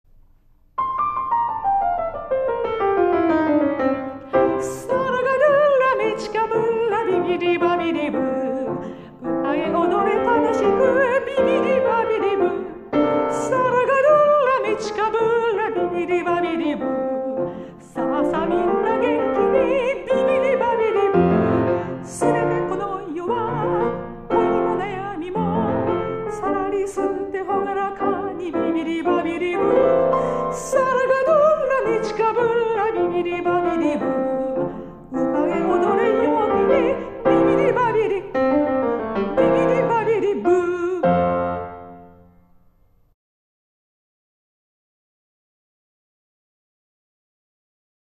メゾ・ソプラノ
ピアノ